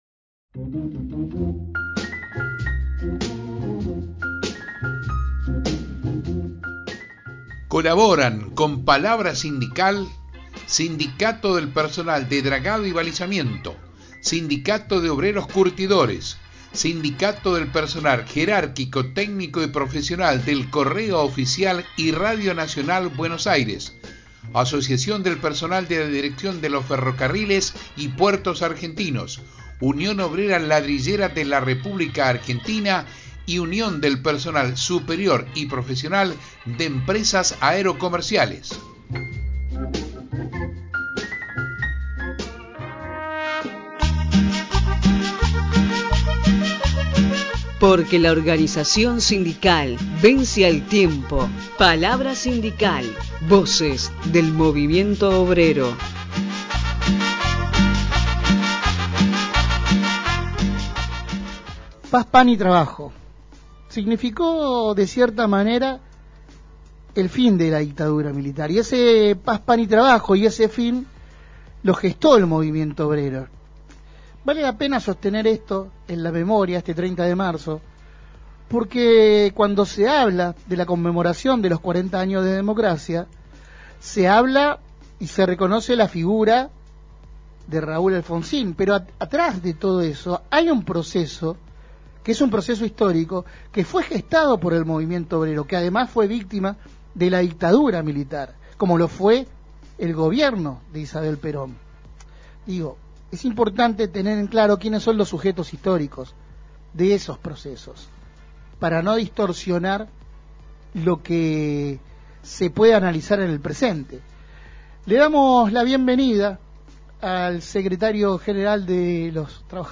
Copartimos la entrevista completa: 30/3/2023